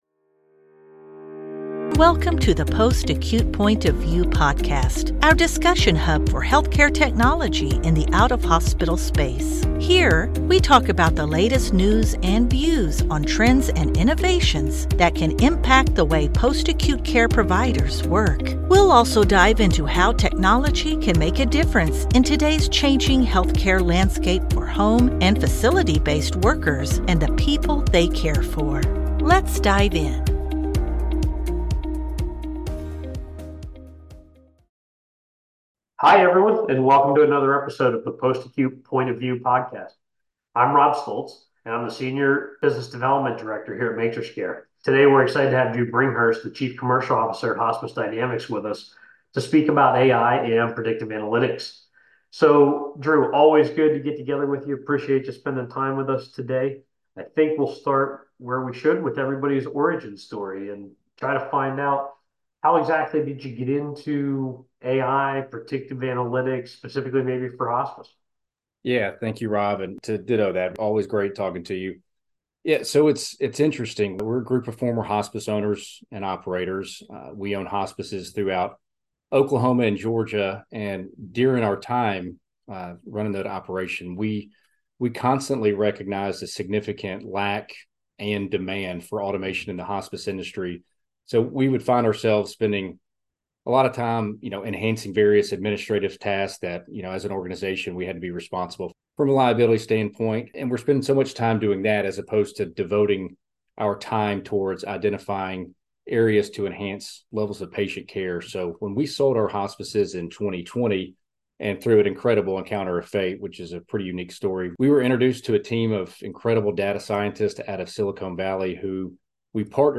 Together, they delve into Hospice Dynamix' use of artificial intelligence and data-driven analytics to accurately designate predictive length of stays (PLOS). Throughout their discussion, the duo explores critical issues related to hospice and palliative care, shedding light on the significance of identifying the right time for hospice care initiation during a patient's stay.